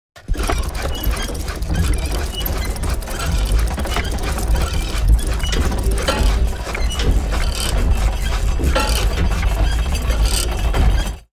Download Free Steampunk Sound Effects
Steampunk